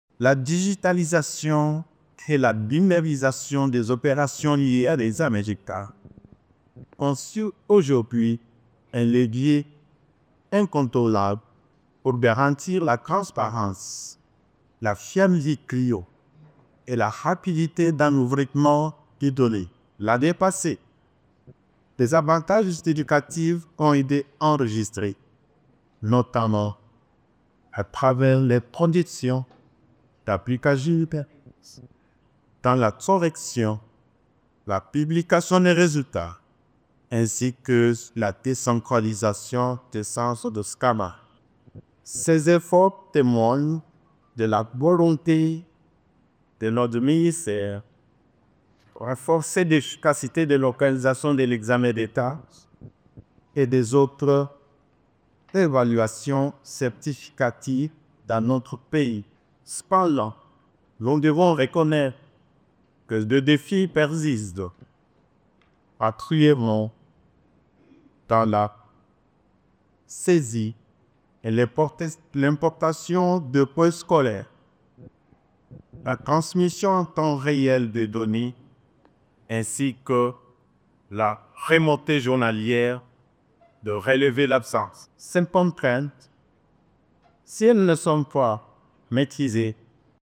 Il organise, du 23 au 26 février à Kinshasa, un atelier sur la modernisation et l’optimisation du processus de gestion des données de cette épreuve nationale.